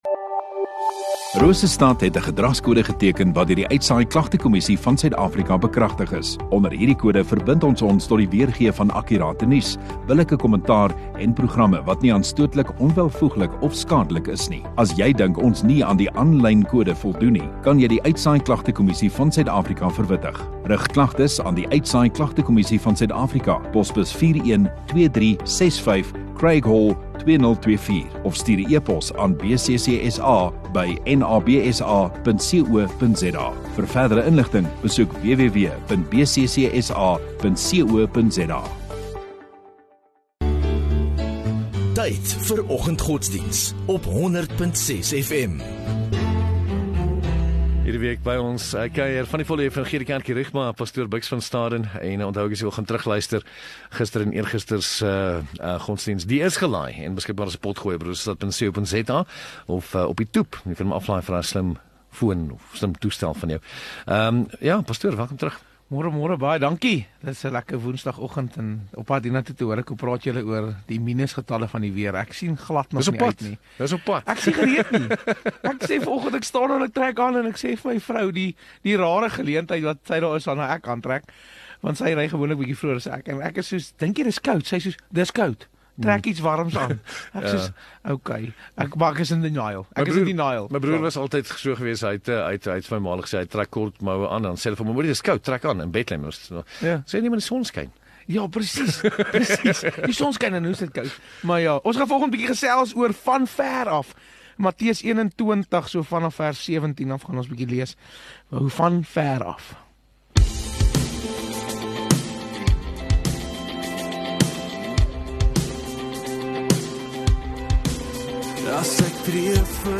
29 May Woensdag Oggenddiens